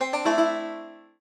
banjo_c1d1ae1e1.ogg